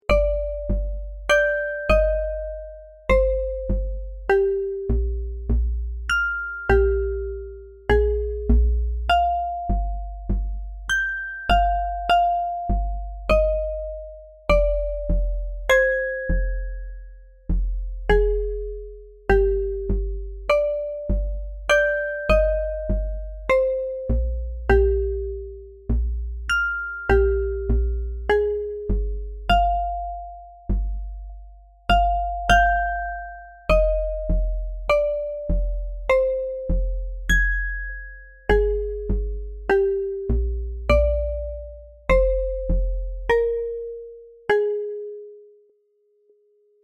破壊された町とかイメージ。ループ対応。
BPM100